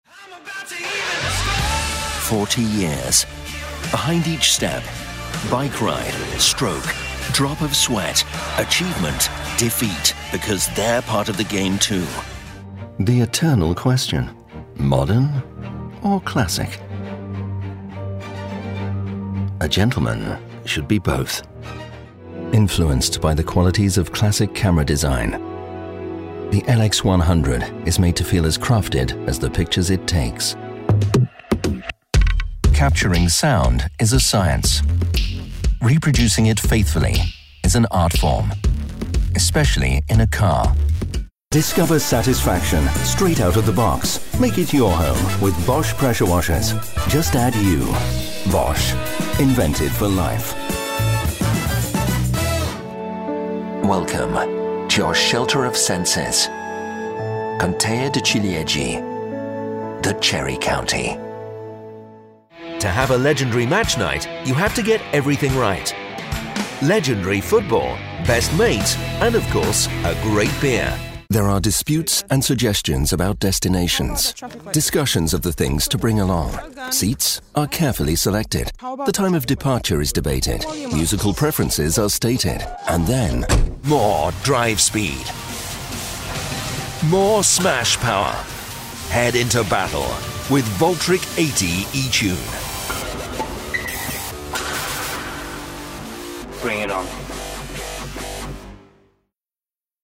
Warm, rich & reassuring British voice, at once approachable and authoritative.
englisch (uk)
Sprechprobe: Werbung (Muttersprache):